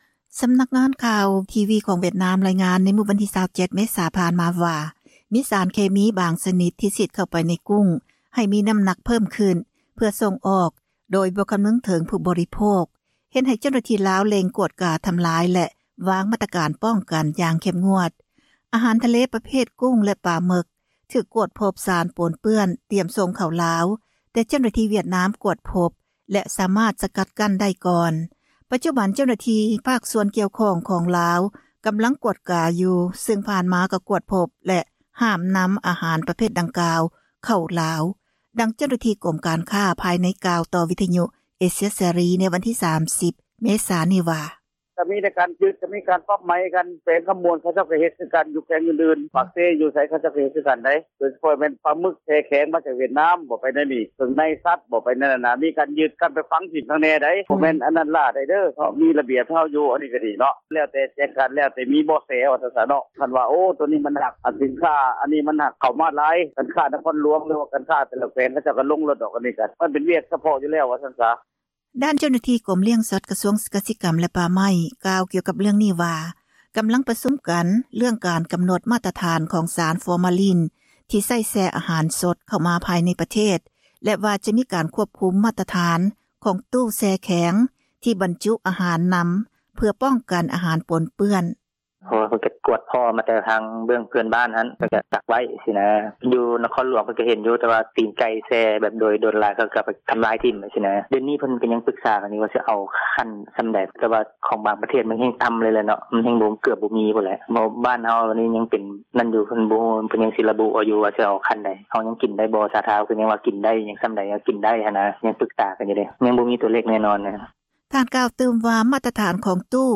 ດັ່ງ ເຈົ້າໜ້າທີ່ກົມການຄ້າພາຍໃນ ກ່າວຕໍ່ ເອເຊັຍເສຣີ ໃນມື້ວັນທີ 30 ເມສານີ້ວ່າ: